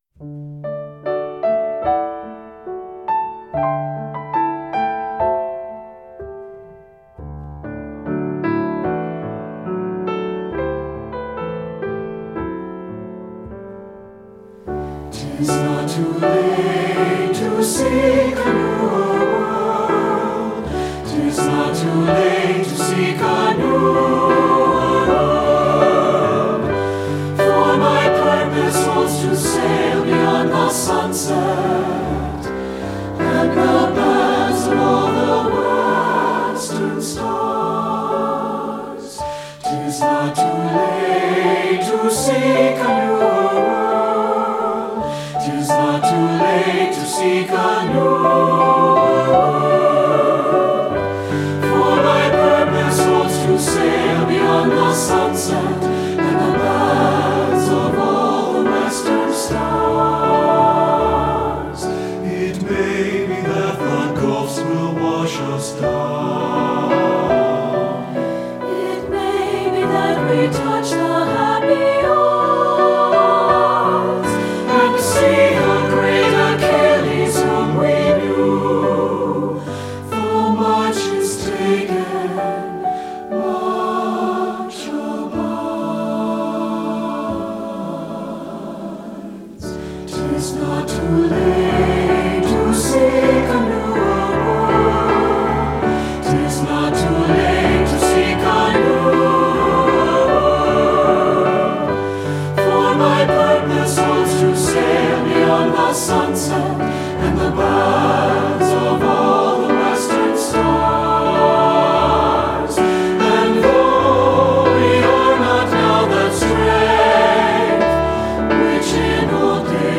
Voicing: TBB and Piano